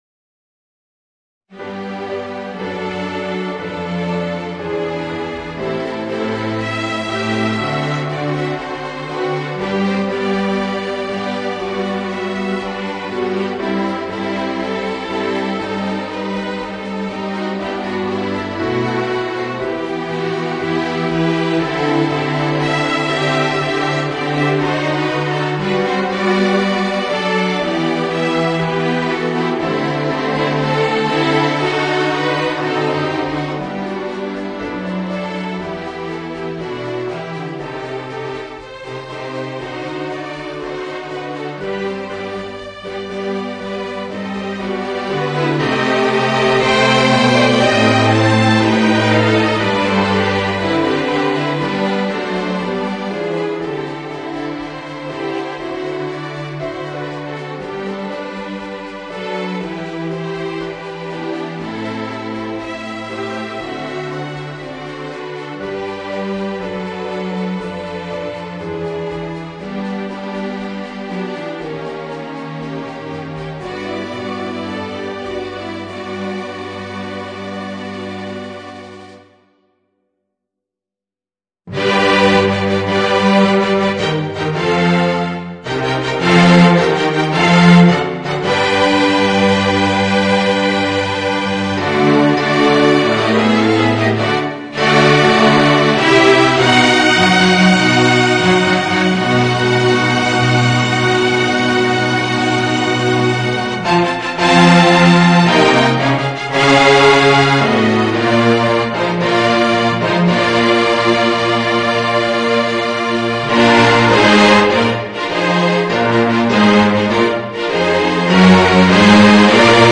Voicing: String Orchestra and Organ